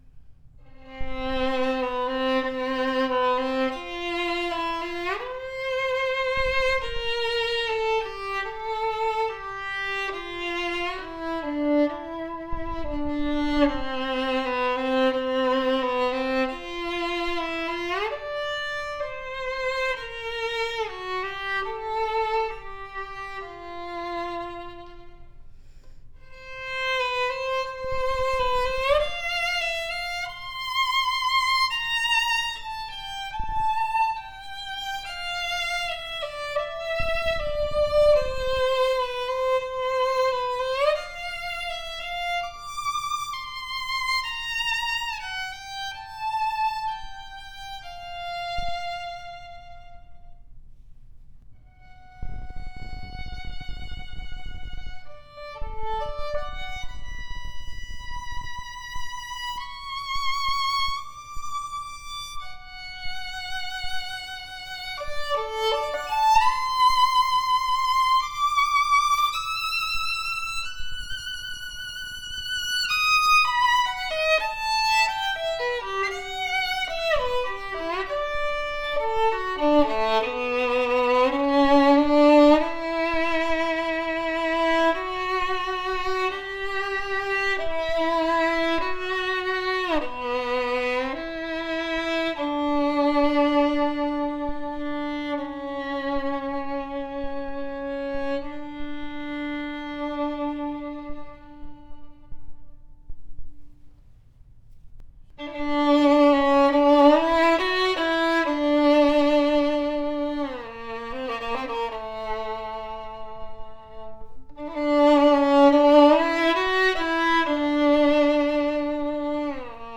A superior “Leduc” Guarneri copy with open and projective tone with fast response, extremely rare at this price range! Professionally set up with high end playability, superior power and projection that will surprise you at this price range. Ringing higher register that projects well and not overly bright, open and pleasant to hear. Bold and resonant G string with a deep open tone. A projective sounding strad that built with seriousness, at very affordable price.